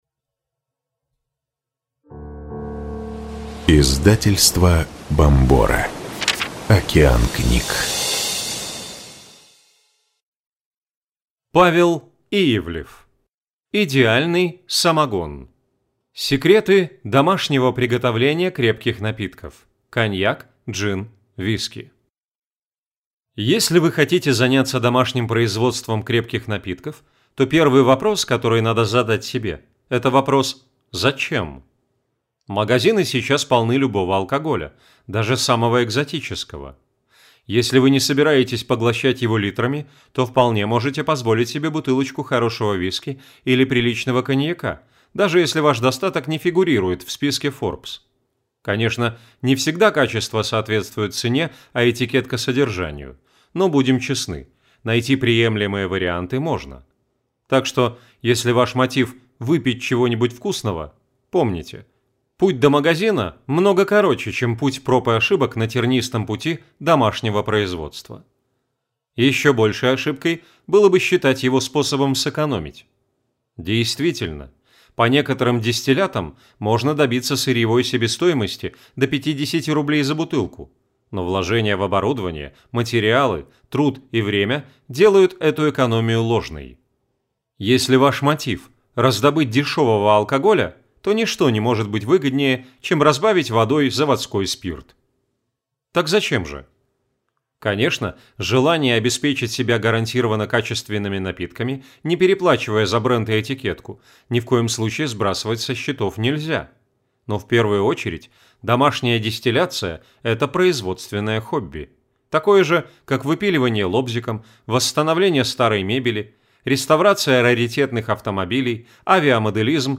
Аудиокнига Идеальный самогон. Секреты домашнего приготовления крепких напитков: коньяк, джин, виски | Библиотека аудиокниг